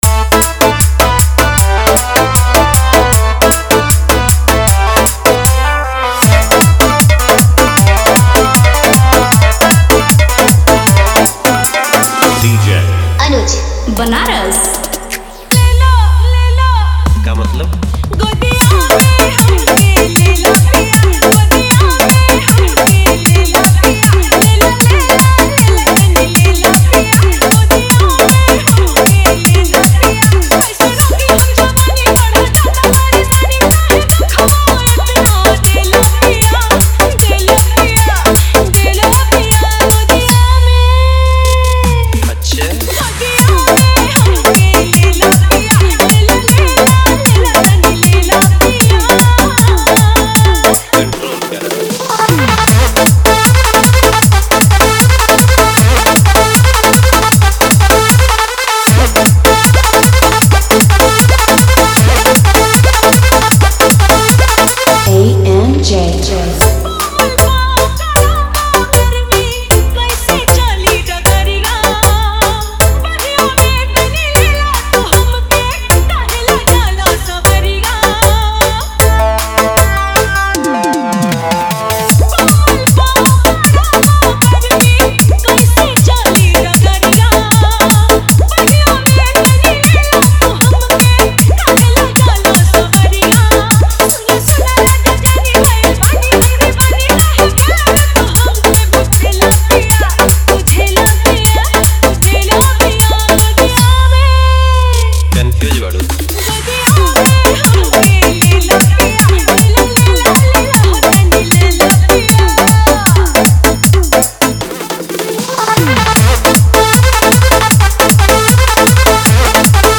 भोजपुरी डीजे सॉन्ग
वोकल: सुपरहिट भोजपुरी गायिका
कैटेगरी: भोजपुरी डांस मिक्स, देसी वायरल सांग
ड्यूरेशन: फुल डीजे कट